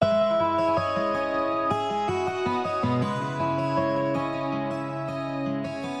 标签： 160 bpm Trap Loops Guitar Electric Loops 1.01 MB wav Key : B
声道立体声